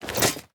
equip_netherite4.ogg